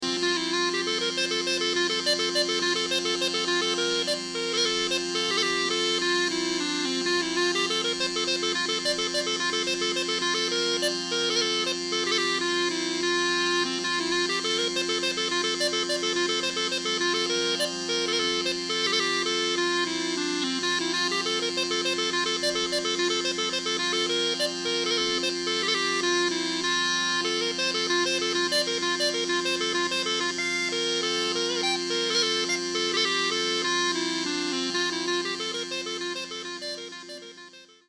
Northumbrian small pipes
Her repertoire consists primarily of traditional tunes and modern tunes in traditional style.